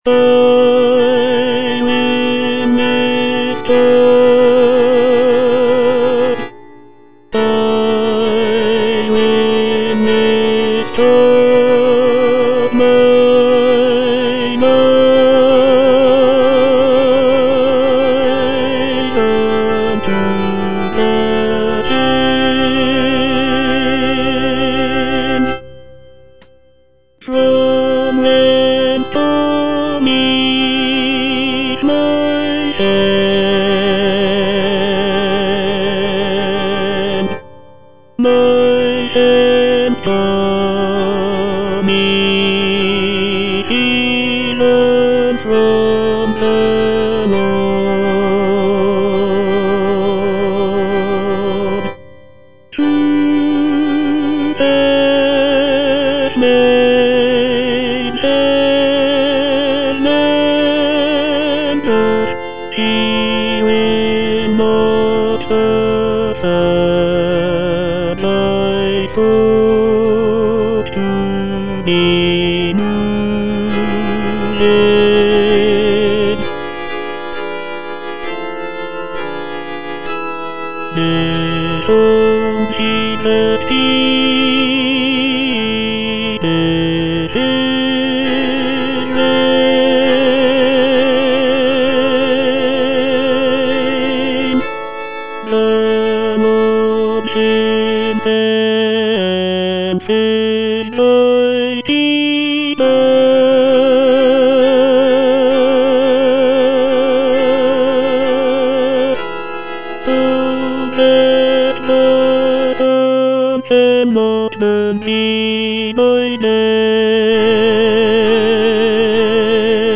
Tenor II (Voice with metronome)
choral work